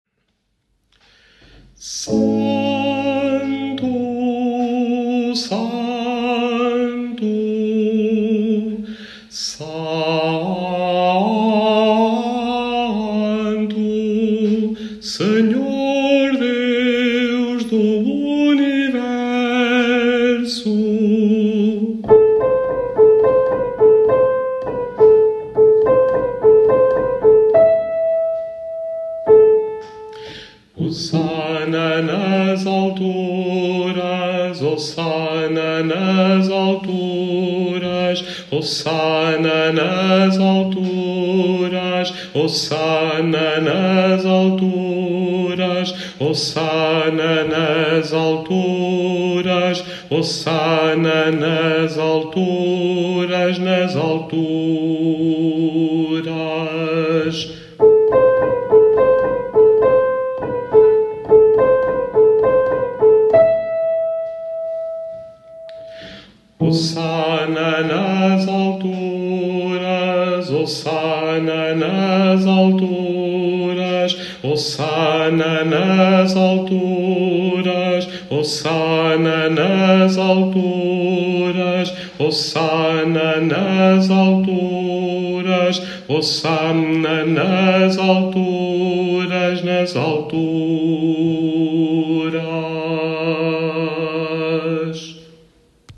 Baixo1